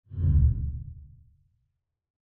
Transition Whoosh Sound Button | Sound Effect Pro
Instant meme sound effect perfect for videos, streams, and sharing with friends.